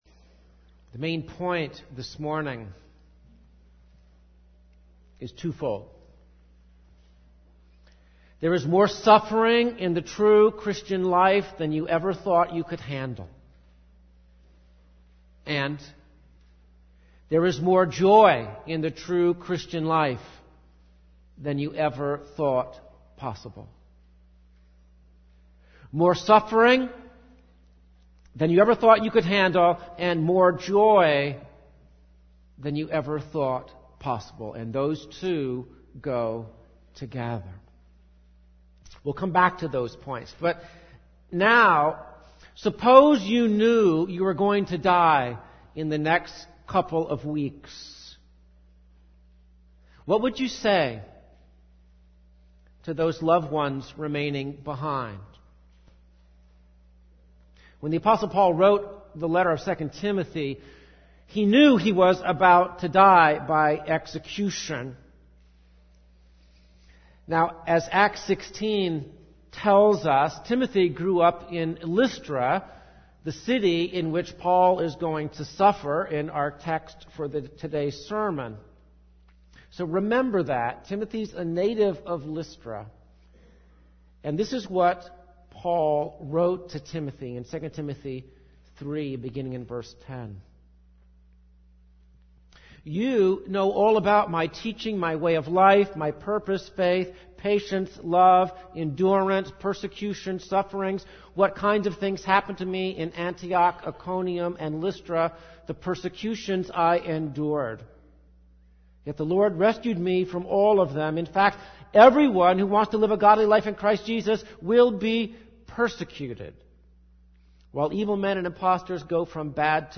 (This sermon on Acts 14 was preached on March 1, 2009. The audio will be posted at this link.)